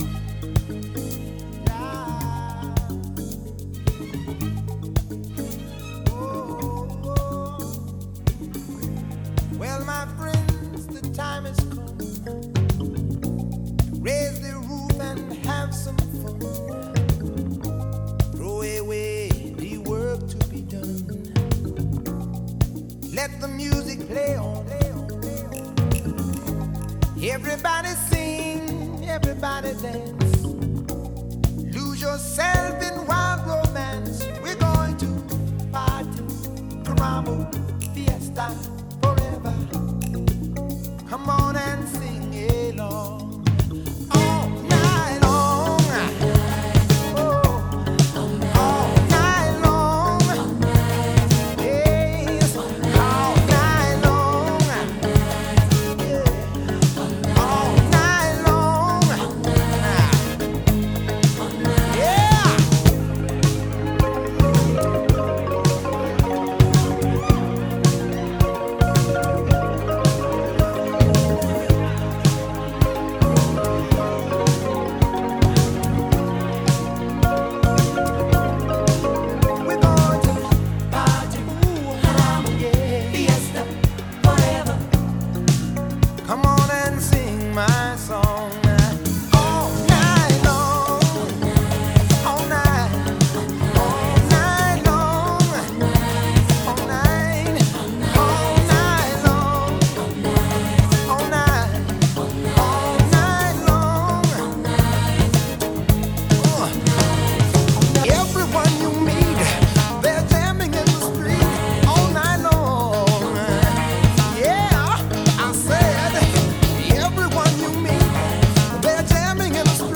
BPM109
Audio QualityLine Out